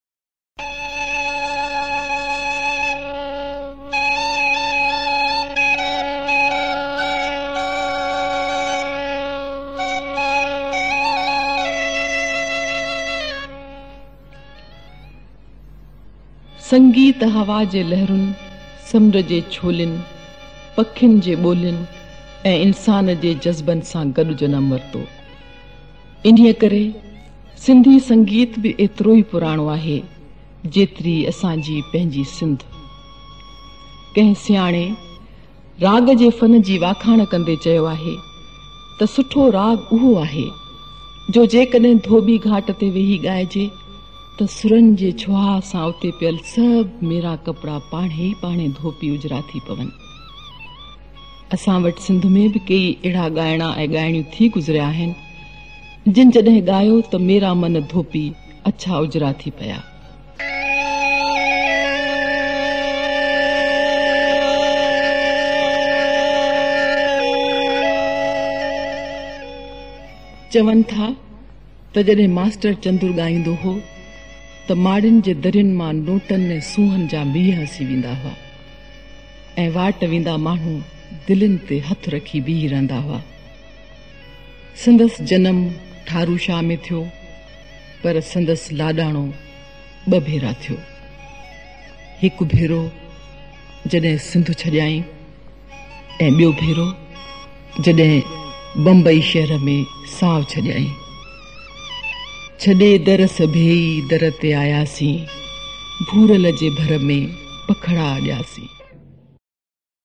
Classic Sindhi Songs